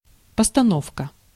Ääntäminen
IPA: /pəstɐˈnofkə/